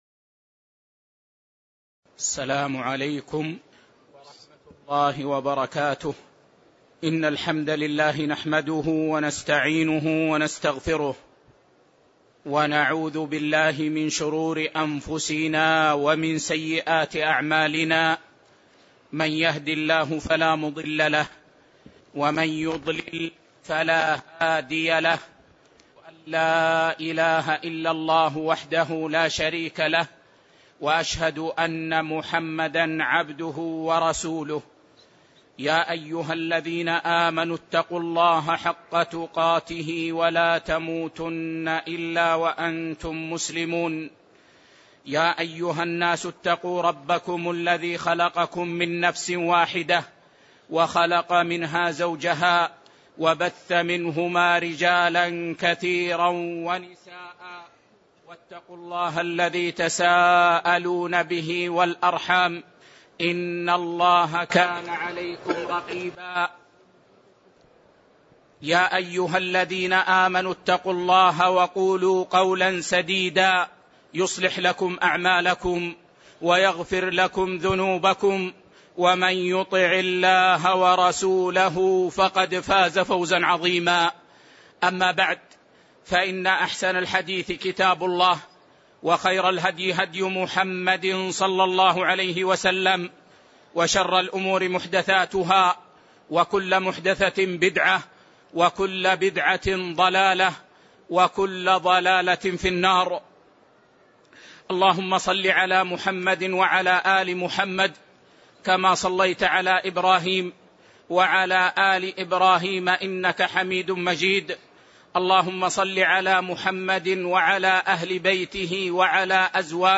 تاريخ النشر ١٩ ربيع الثاني ١٤٣٧ هـ المكان: المسجد النبوي الشيخ